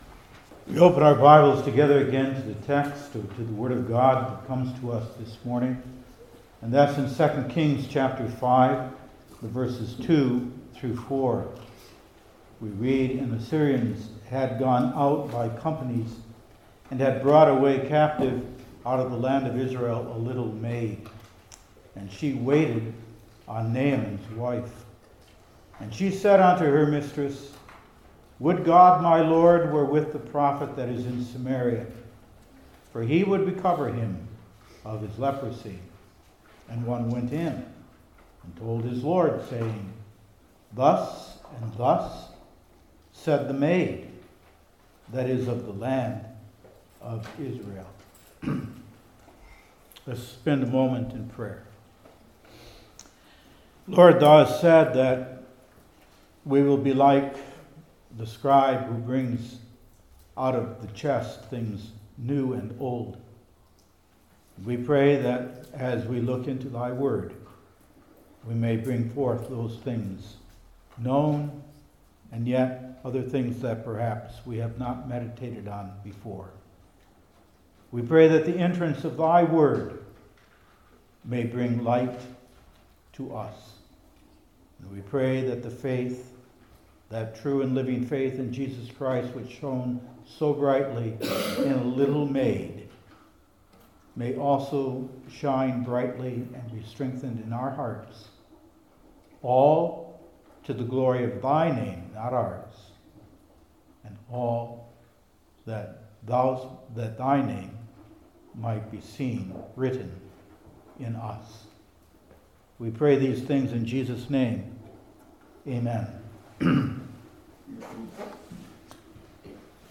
Old Testament Individual Sermons I. Her Spontaneous Faith II.